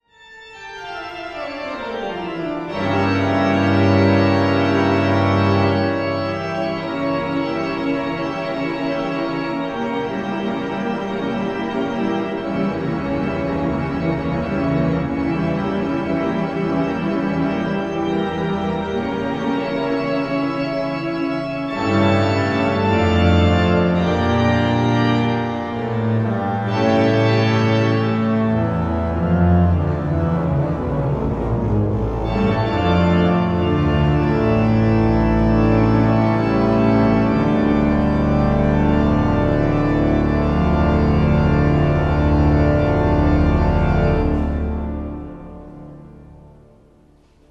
BACH, Toccata et Fugue D minor Bwv 565
Riga Cathedral